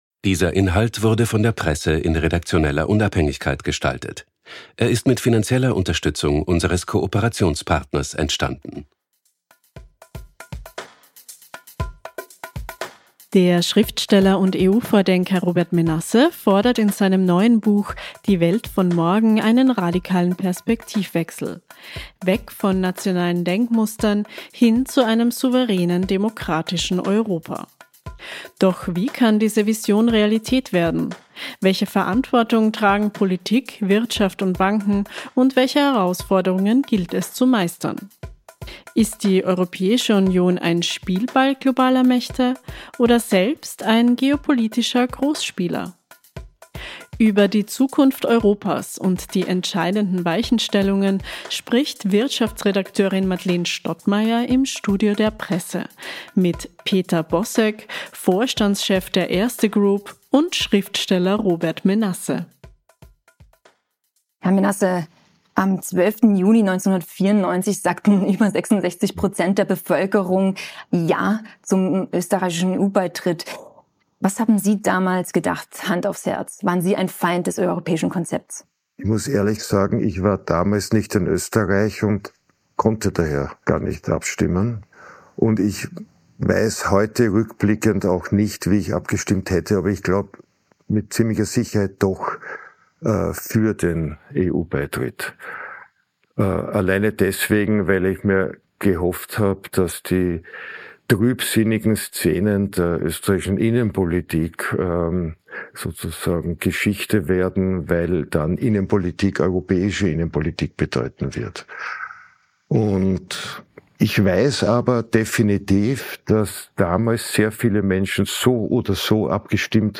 Der Nachrichten-Podcast der Tageszeitung "Die Presse" erscheint wochentags Montag bis Freitag um 18 Uhr und zu besonderen Anlässen wie an Wahlsonntagen auch zwischendurch. Die Redaktion der "Presse" sagt, was wichtig ist und nimmt Sie mit auf Recherche und zu spannenden Gesprächspartnern.